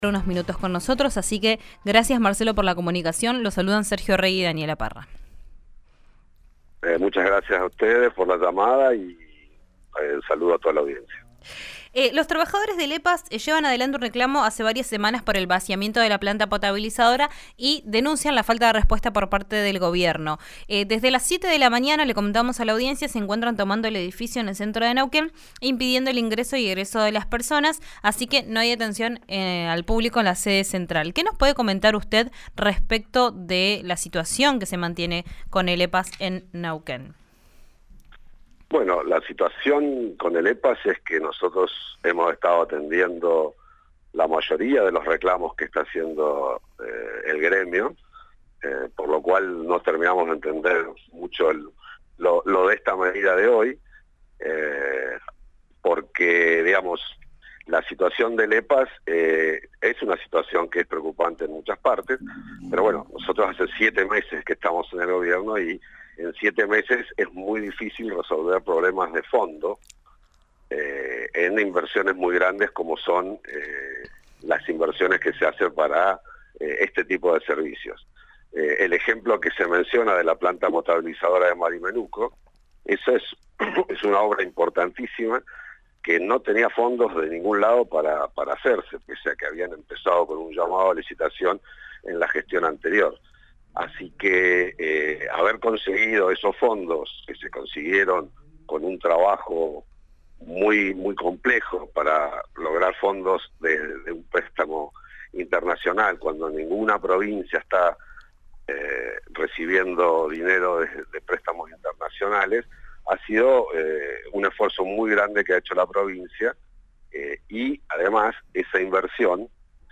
¿Cuál fue la respuesta del Gobierno? Escuchá a secretario de Empresa Públicas de Neuquén, Marcelo Lascano: